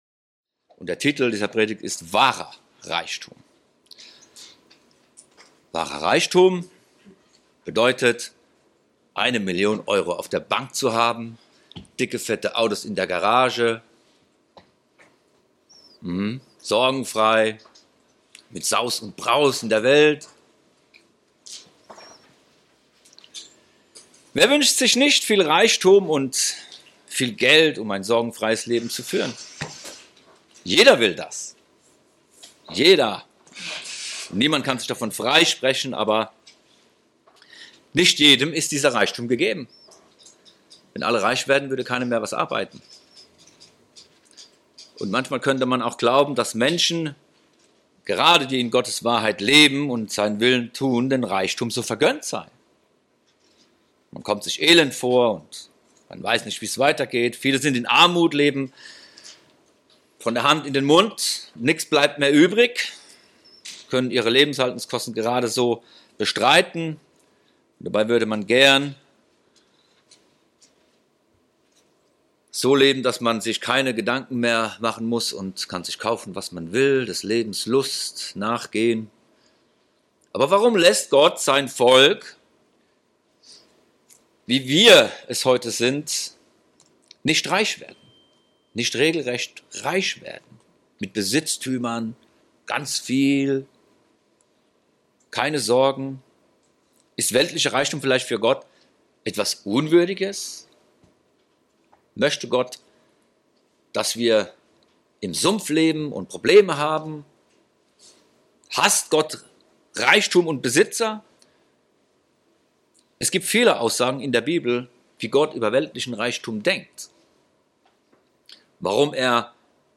All dies wird in dieser Predigt erklärt.